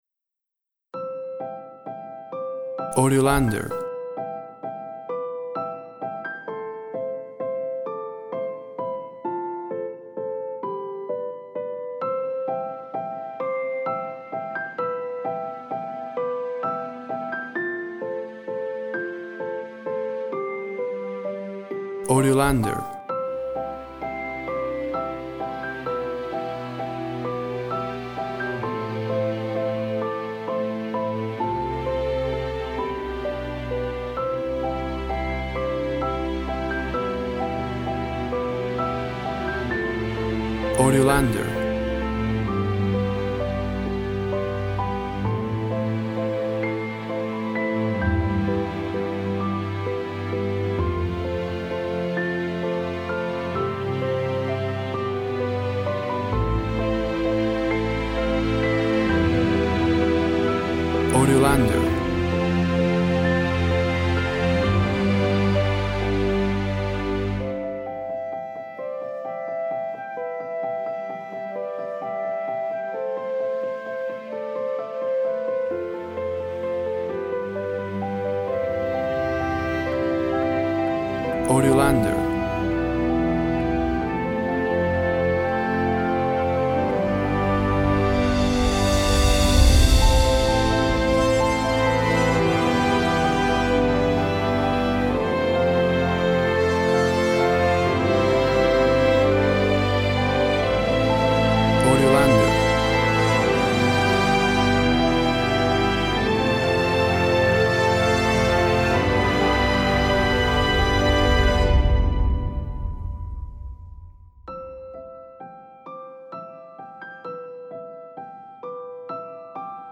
Tempo (BPM) 130